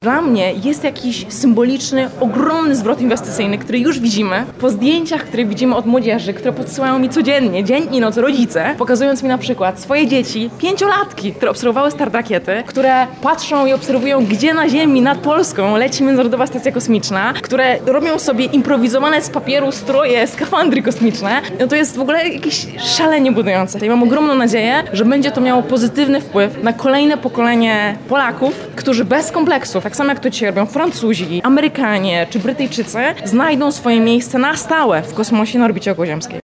Uczniom z Lubartowa w tej wyjątkowej chwili towarzyszą reporterki Radia Lublin, które relacjonują dla naszych słuchaczy, a także odbiorców w mediach społecznościowych to unikatowe wydarzenie.